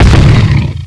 pl_fallpain3-7.wav